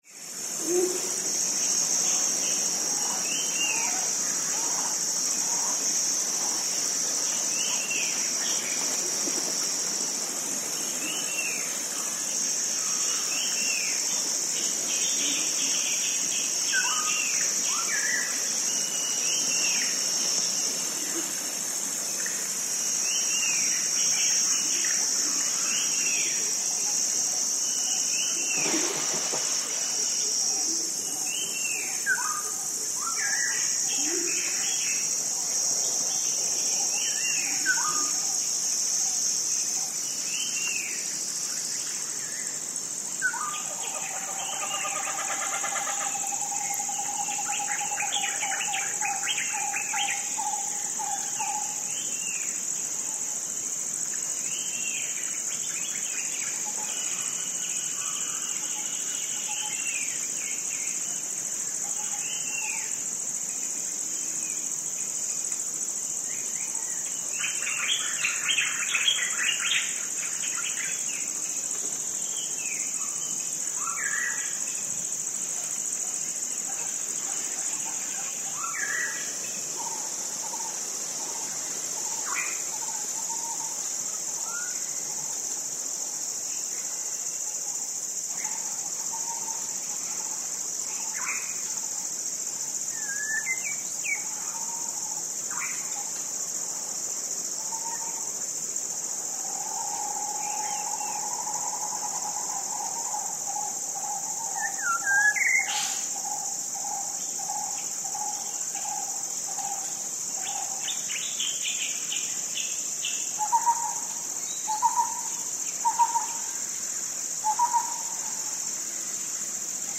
This page lists the animal species which are heard in the foreground or the background in the soundscapes featured on the map.
Wilpattu National Park: recorded at 1.32 pm on 12 September 2011
Tufted Grey Langur, Brown-headed Barbet, Brown-capped Babbler, Black-hooded oriole, White-browed Bulbul, Indian Palm Squirrel, Sri Lanka Junglefowl, Common Iora, Sri Lanka Grey Hornbill, Flameback sp., White-rumped Shama, Cicada sp. and other insects.